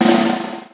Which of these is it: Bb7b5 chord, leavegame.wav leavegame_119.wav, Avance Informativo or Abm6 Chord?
leavegame.wav leavegame_119.wav